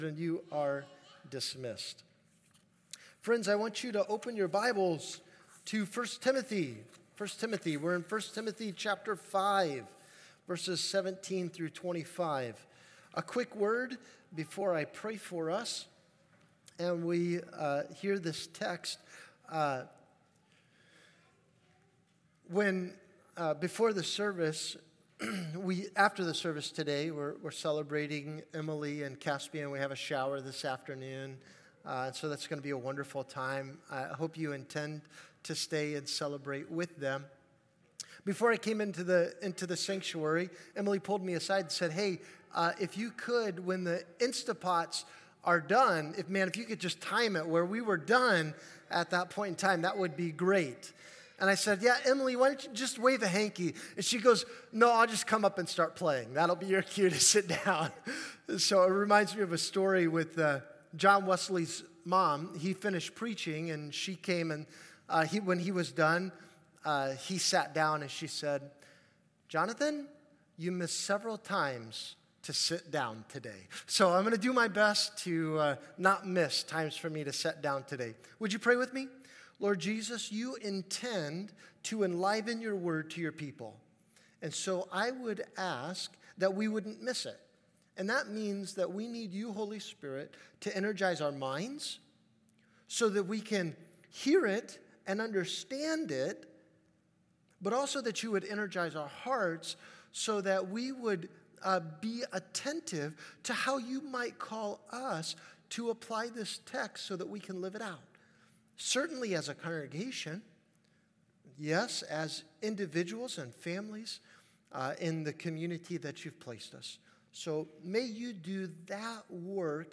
Sermons | California Road Missionary Church